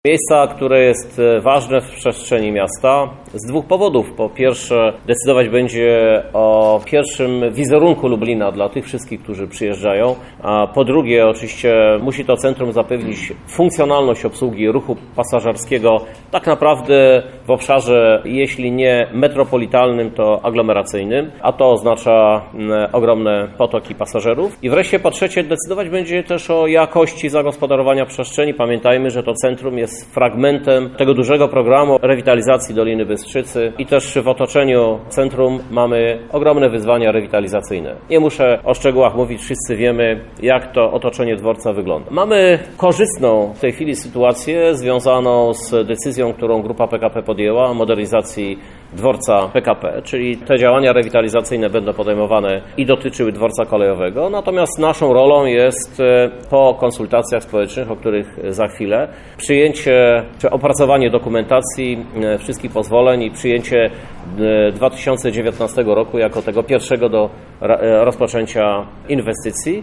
O tym co zadecydowało o powstaniu nowego dworca mówi prezydent Krzysztof Żuk